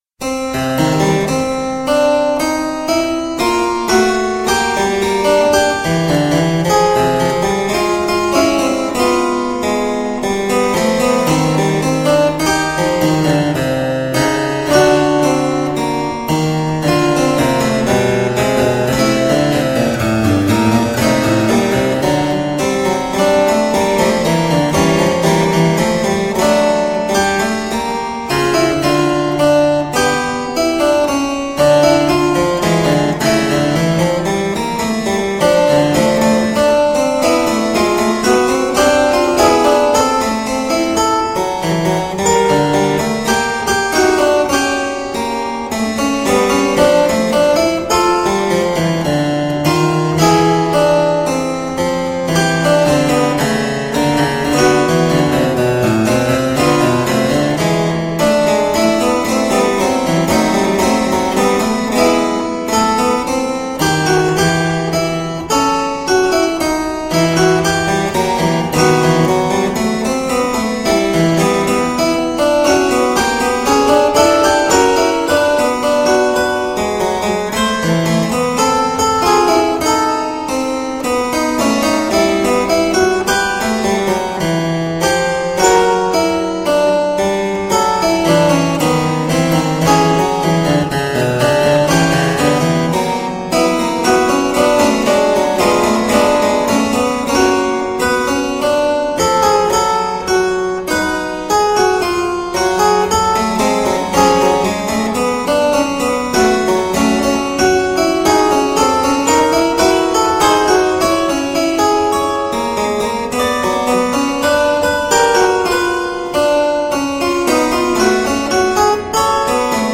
1. Il canone inverso o retrogrado, noto anche come cancrizzante, dal latino cancer gambero, come il gambero cammina all’indietro: la voce conseguente inizia dall’ultima nota della voce antecedente e prosegue all’indietro, terminando con quella iniziale.
Bach-Canone_Inverso.mp3